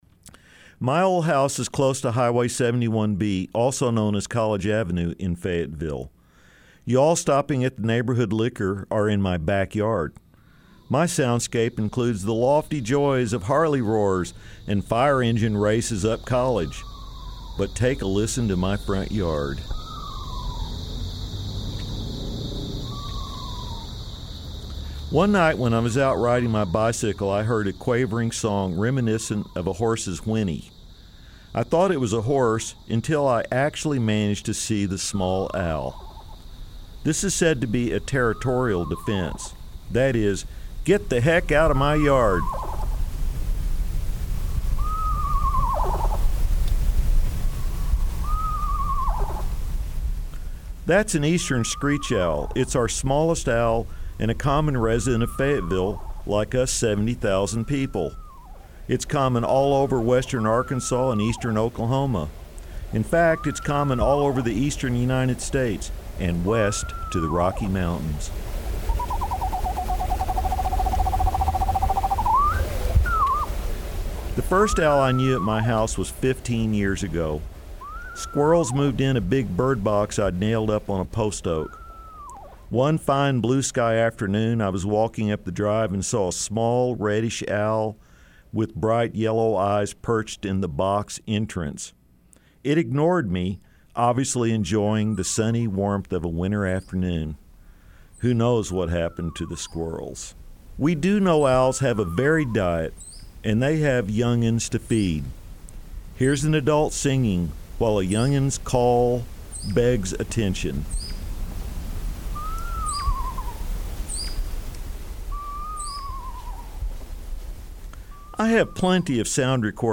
Owl_Screech_0.mp3